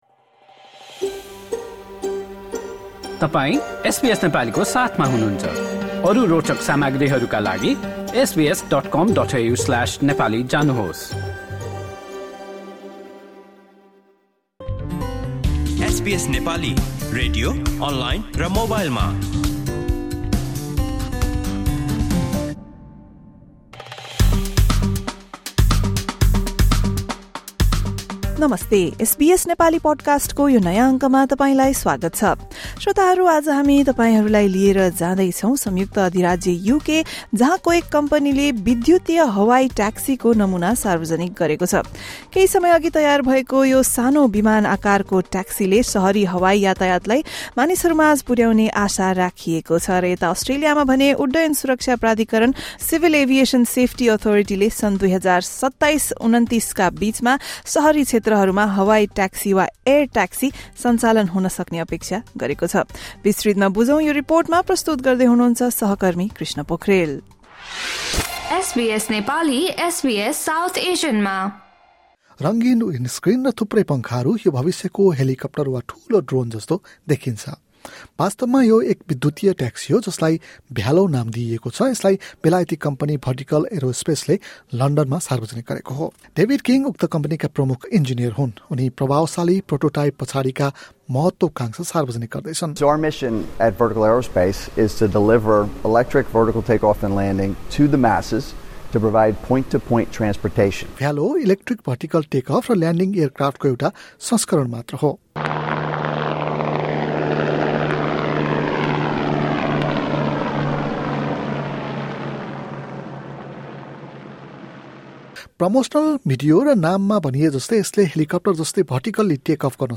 अस्ट्रेलियाको नागरिक उड्डयन सुरक्षा प्राधिकरणले भने सन् २०२७ र २०२९ का बिचमा सहरी क्षेत्रहरूमा हवाई ट्याक्सी सञ्चालन हुन सक्ने अपेक्षा गरेको छ। एक रिपोर्ट।